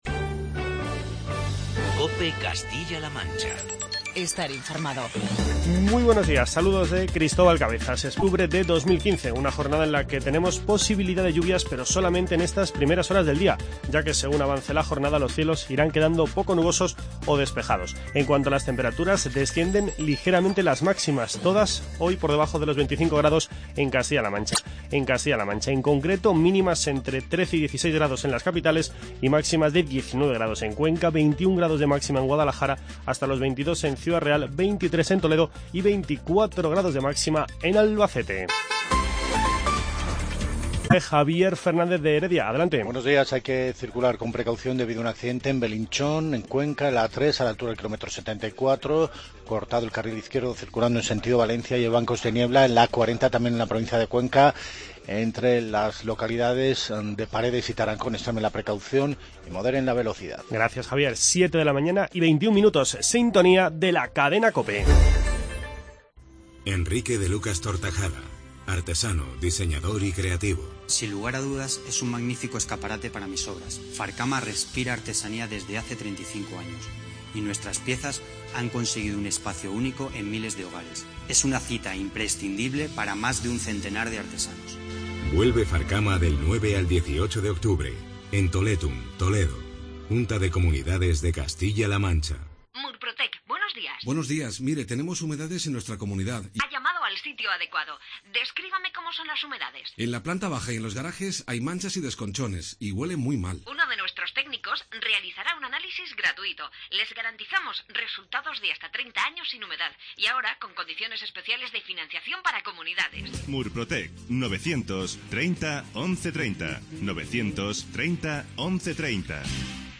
Informativo regional
El alcalde de Sacedón, Francisco Pérez Torrecilla, explica en COPE Castilla-La Mancha que el volumen de negocio ha caído un 80% debido a la situación de escasez de agua en los embalses de la cabecera del Tajo